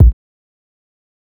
old SIZZ kick.wav.wav